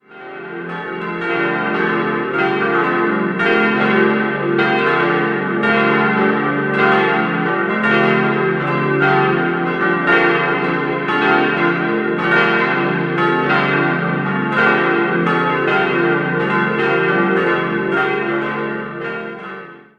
Letztere wurde in den Jahren 1962 bis 1964 errichtet, die Fertigstellung der Ausstattung zog sich noch einige Jahre hin. 5-stimmiges Geläut: d'-f'-g'-a'-c'' Alle Glocken wurden im Jahr 1963 von Friedrich Wilhelm Schilling in Heidelberg gegossen.